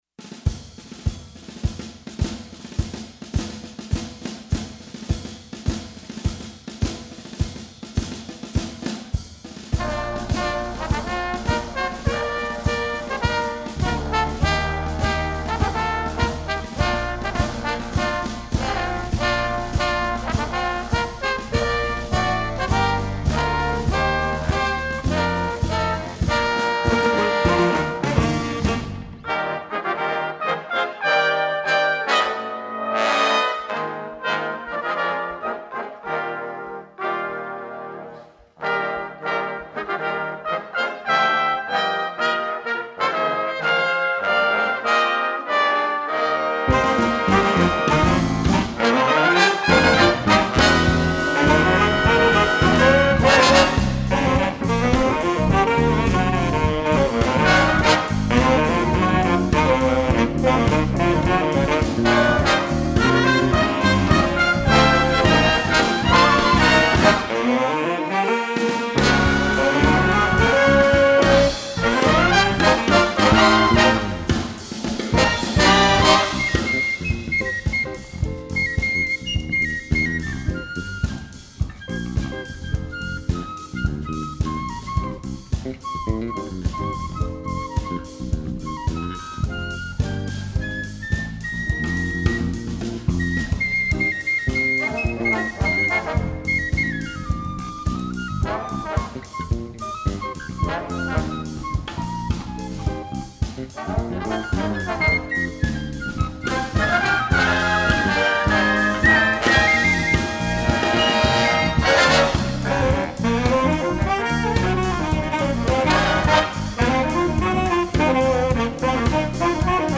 Studio Recordings: